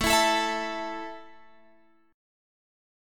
A5 chord {17 19 19 x 17 17} chord